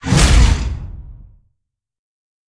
Sfx_creature_bruteshark_flinch_01.ogg